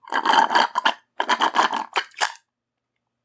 Finish drinking juice box (sound effects)
A sound effect that finishes drinking Juice box....